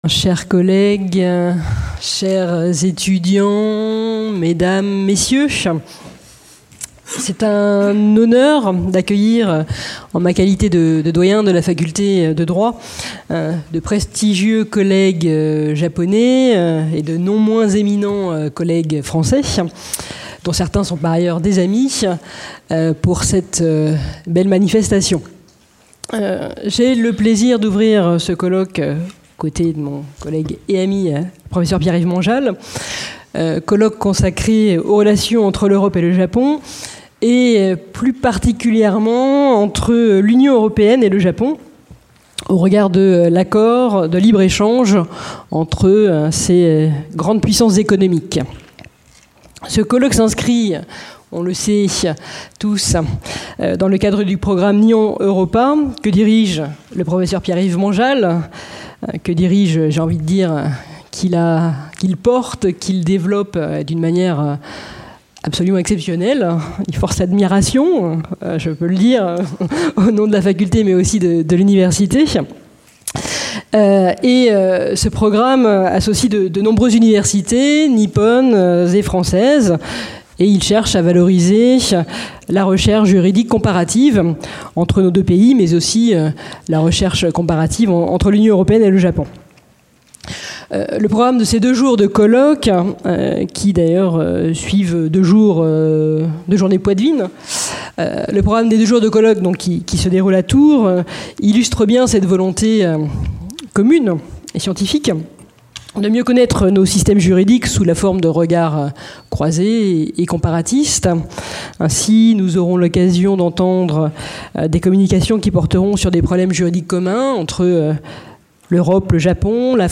Discours de bienvenue